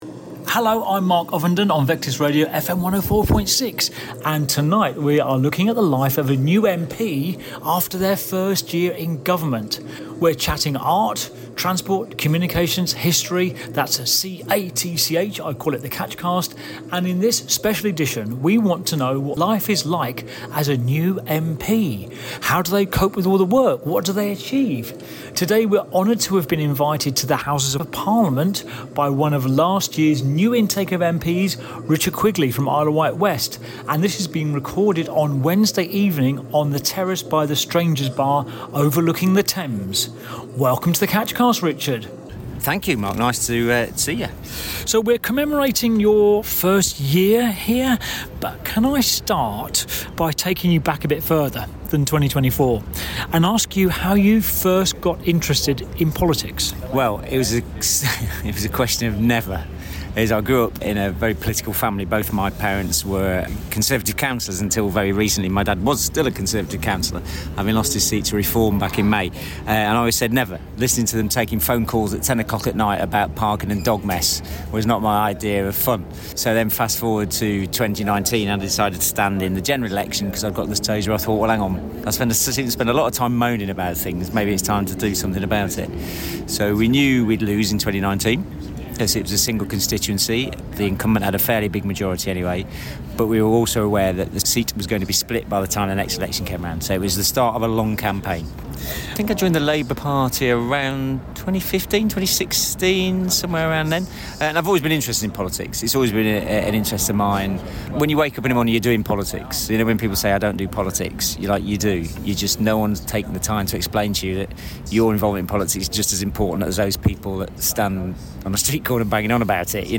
After a year in Government, Isle of Wight West MP Richard Quigley has gone from ferry improvement champion to Welfare Reform rebel. In this exclusive interview he reveals how he got the PM's ear and what he wants to happen next.